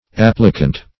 Applicant - definition of Applicant - synonyms, pronunciation, spelling from Free Dictionary
Applicant \Ap"pli*cant\, n. [L. applicans, p. pr. of applicare.